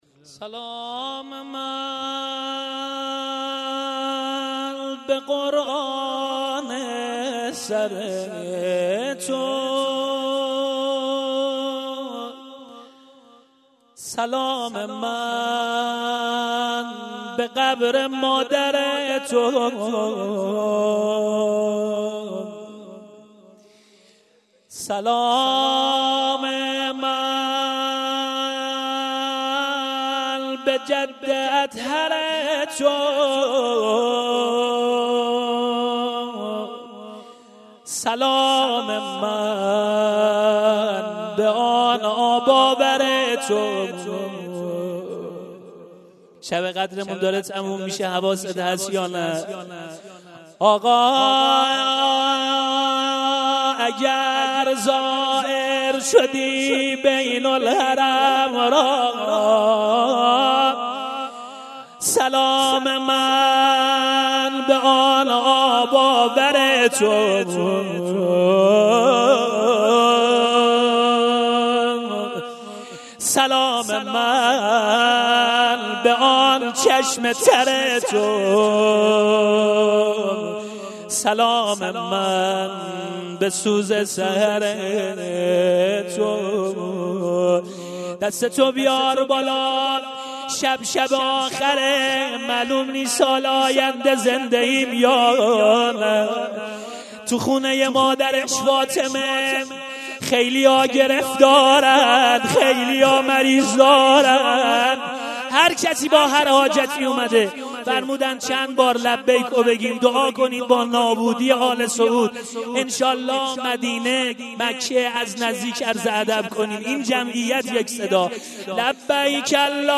فایل های صوتی شب بیست و سوم ماه مبارک رمضان۱۳۹۷